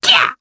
Lakrak-Vox_Jump_jp.wav